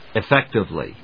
ef・féc・tive・ly
音節ef･fec･tive･ly発音記号・読み方ɪféktɪvli変化more ; most